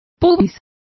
Complete with pronunciation of the translation of pubises.